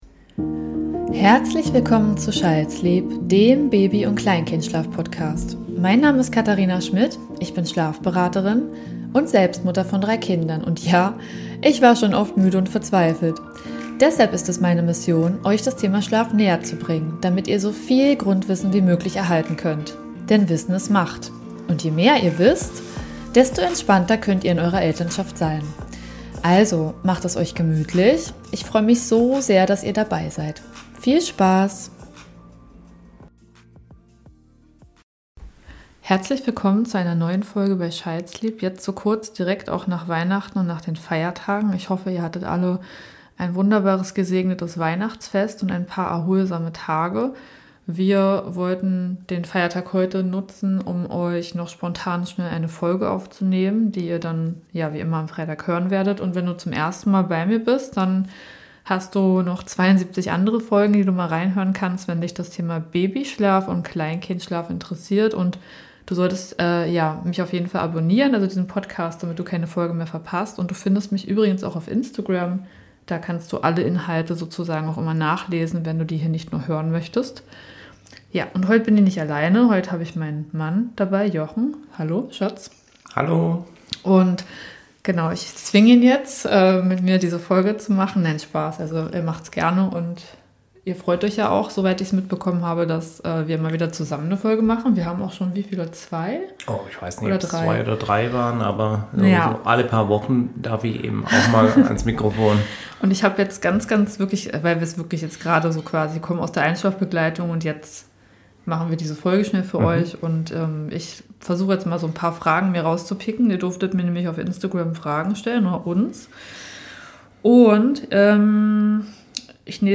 Gemeinsam mit meinem Mann beantworte ich eure spannenden Fragen rund ums Elternsein, Paarzeit und die Schlafbegleitung unserer Kinder.  Freut euch auf ehrliche Einblicke und praktische Tipps!